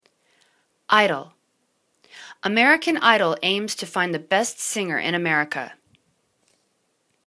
idol    /'i:dl/    n